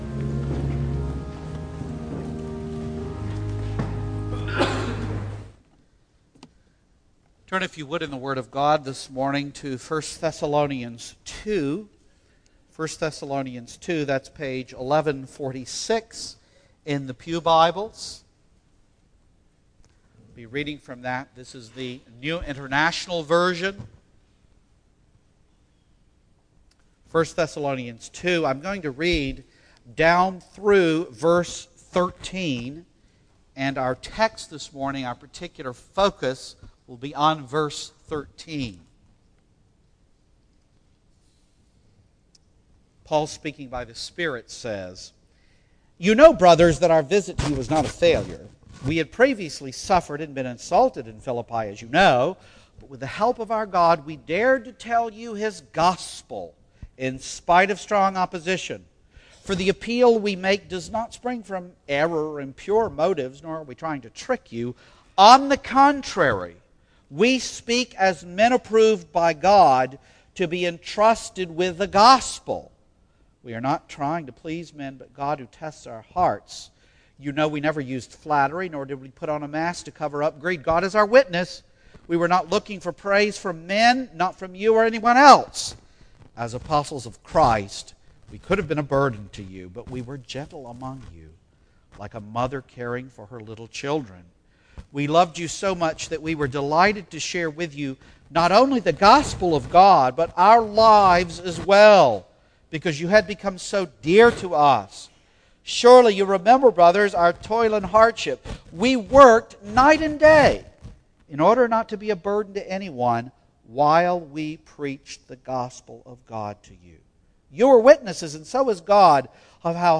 Single Sermons Passage: 1 Thessalonians 2:1-13 %todo_render% « More Than Conquerors 19